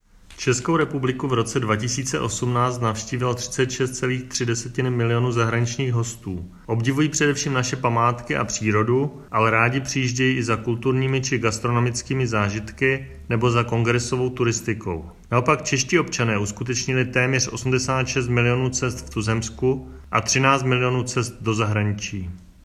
Vyjádření Marka Rojíčka, předsedy ČSÚ, soubor ve formátu MP3, 886.92 kB